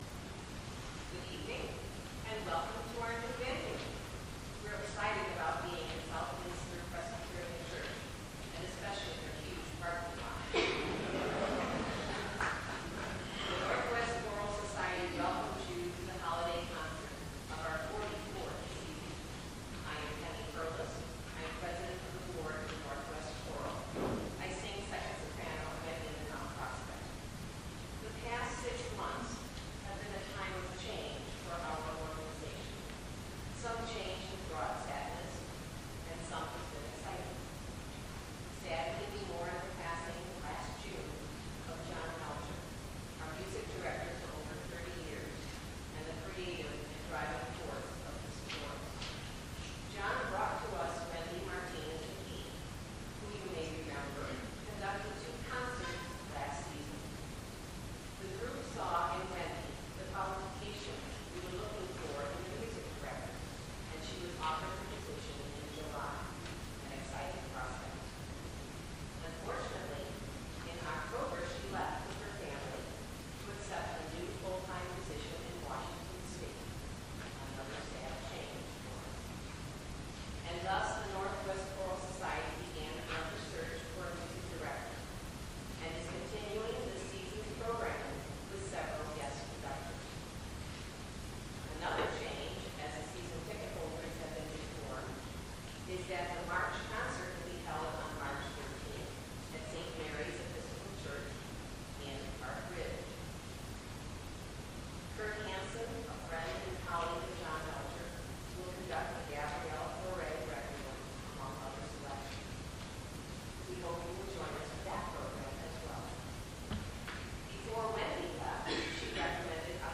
Holiday Concert :: Season of Wonders
Opening Remarks